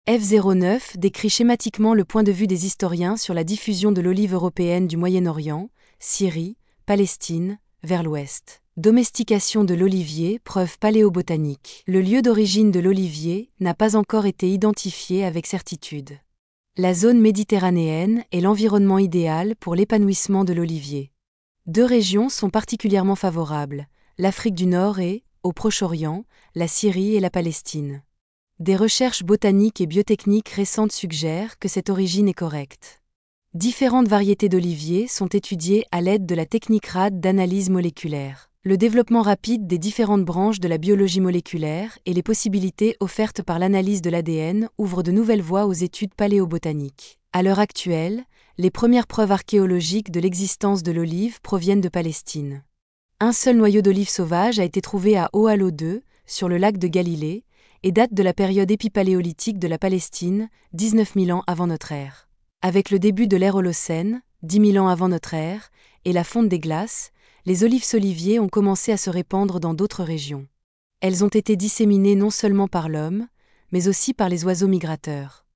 Visite guidée audio